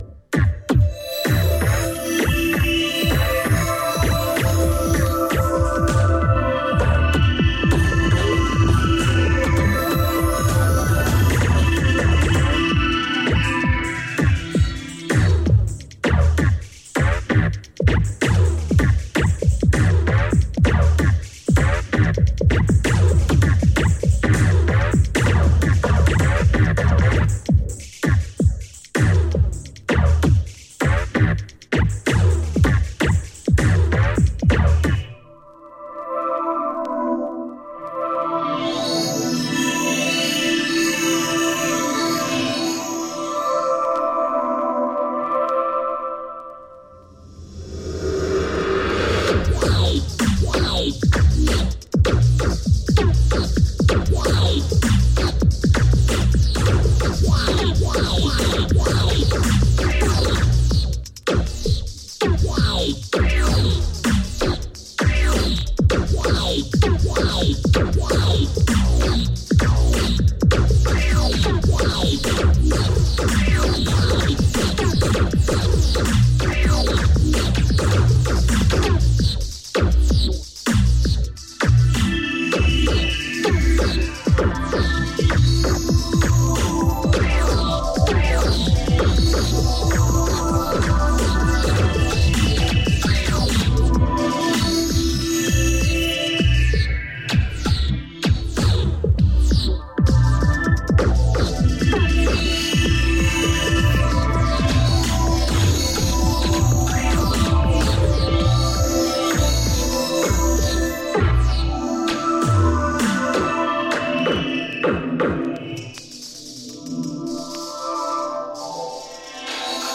Electronix